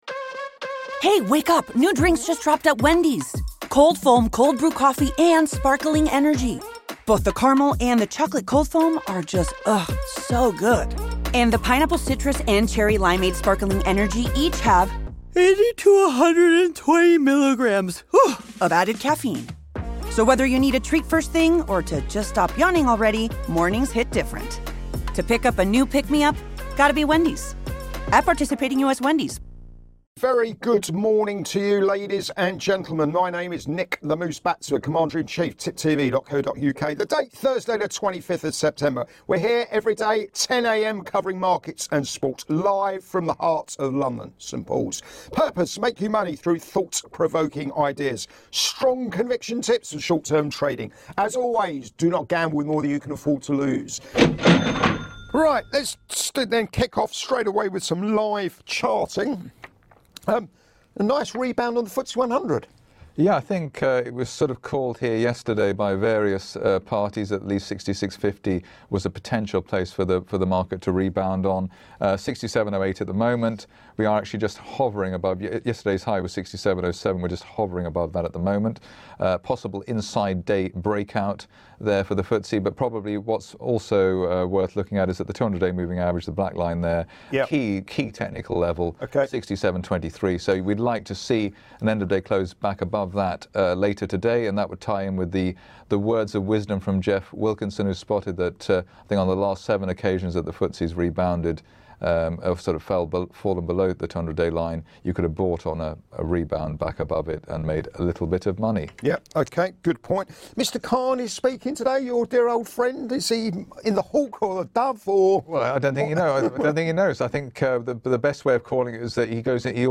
Live Market Round Up